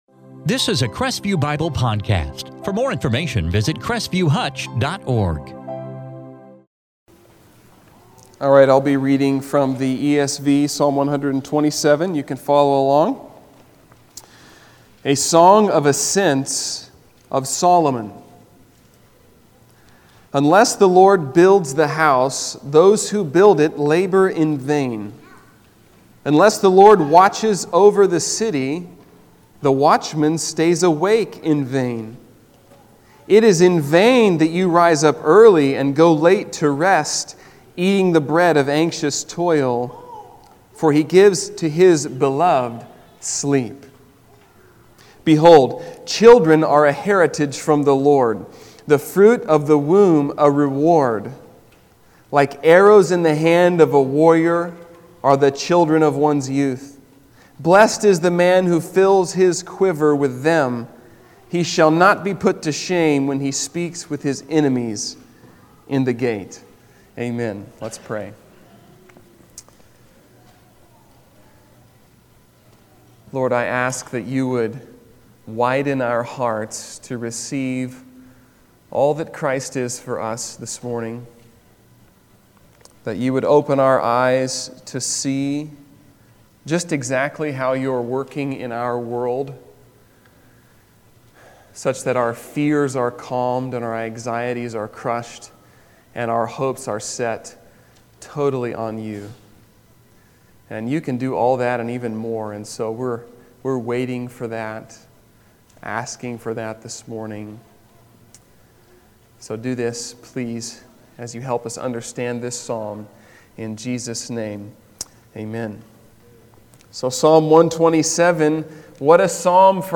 2020 Psalms of Ascent Psalm Transcript In this sermon from Psalm 127